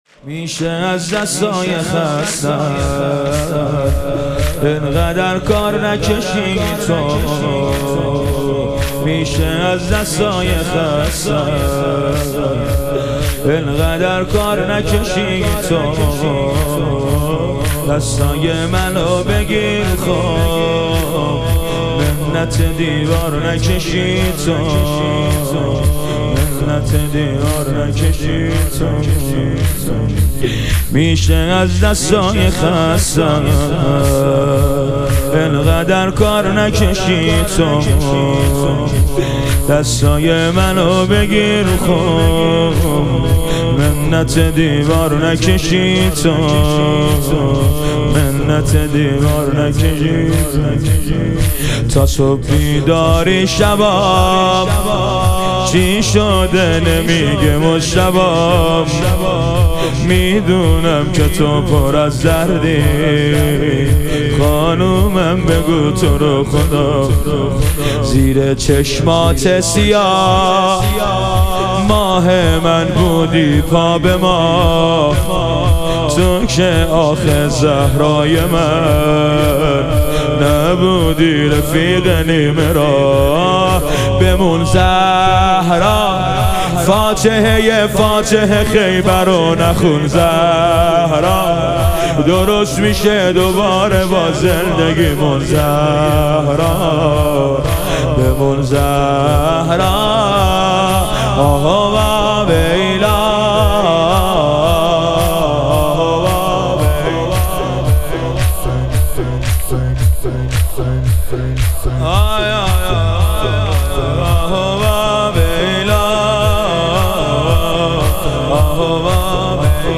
ایام فاطمیه دوم - تک